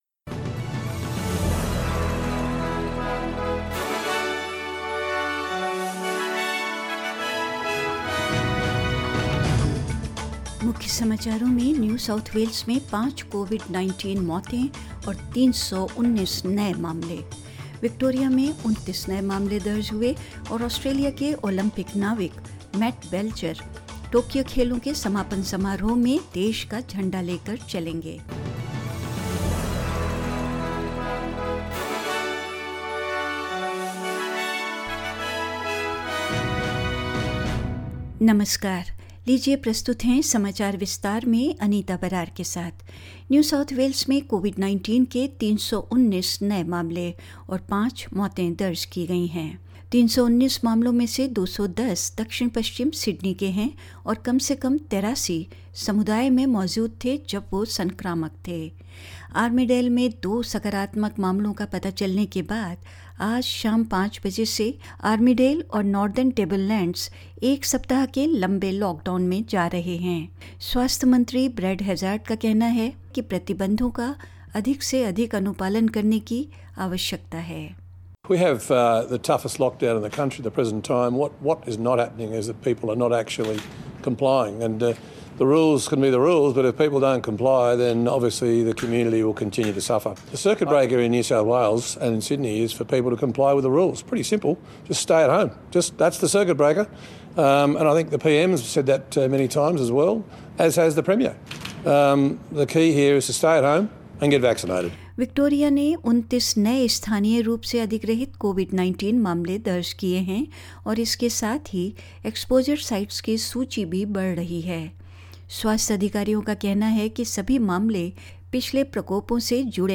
In this latest SBS Hindi News bulletin of Australia and India: Five COVID-19 deaths and 319 new cases in New South Wales; Victoria records 29 new cases; Australia's Olympic sailor Mat Belcher will carry the nation's flag at the closing ceremony of the Tokyo Games; India's Aditi Ashok narrowly missed Olympic medal in golf and more